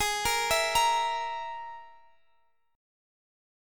Listen to G#sus2#5 strummed